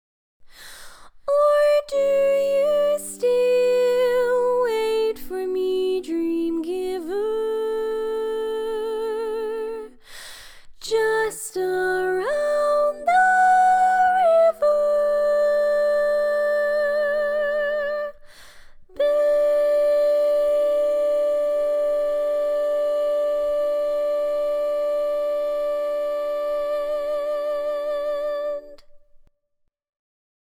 Key written in: D Major
Type: Female Barbershop (incl. SAI, HI, etc)
Comments: Tenor melody